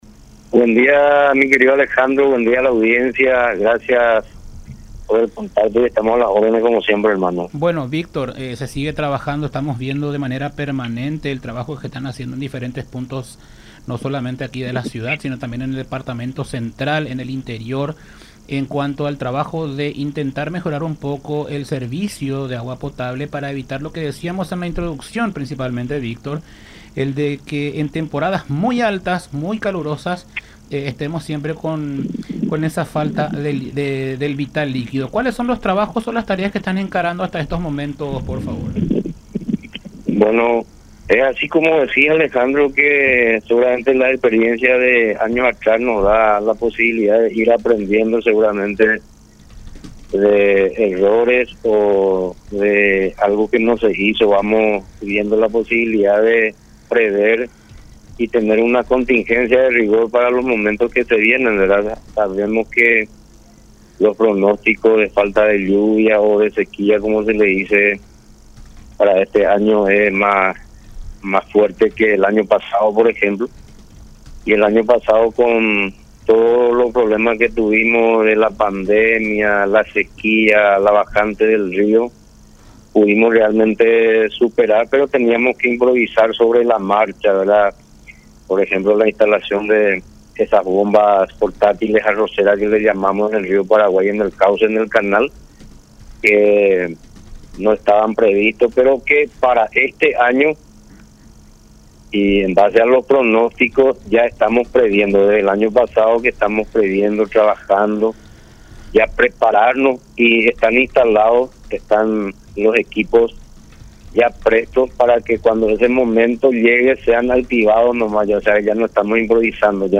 en conversación con Enfoque 800 a través de La Unión.